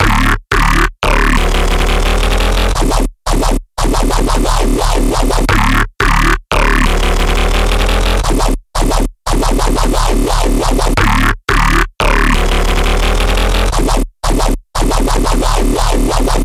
强烈的鼓点下降
Tag: 175 bpm Dubstep Loops Bass Wobble Loops 2.77 MB wav Key : C